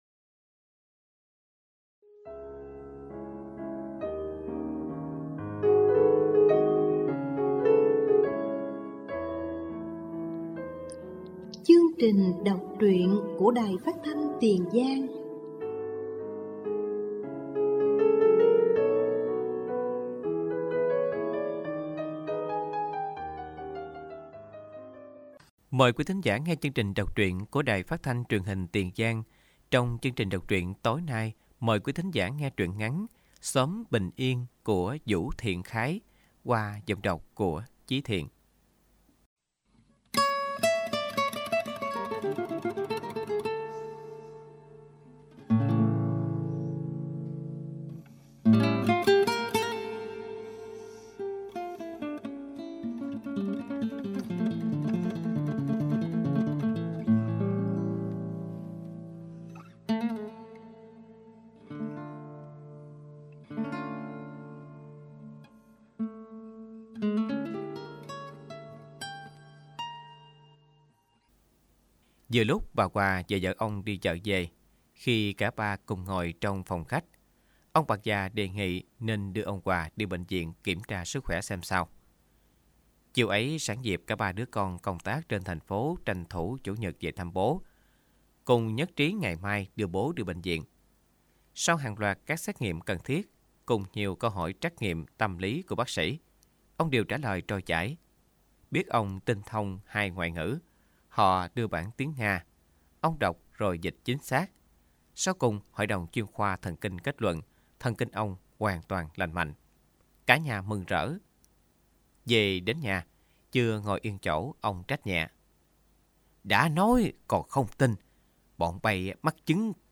Đọc truyện “Xóm bình yên”